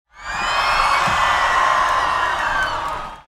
Loud Rock Concert Crowd Cheering Short Sound Effect
Description: Loud rock concert crowd cheering short sound effect. Human sounds.
Loud-rock-concert-crowd-cheering-short-sound-effect.mp3